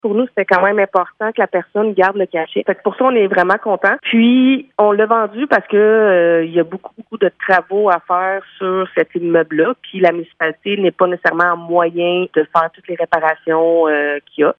La mairesse de Montcerf-Lytton, Véronique Danis, précise que le coût des rénovations dépasse largement les capacités de la Municipalité :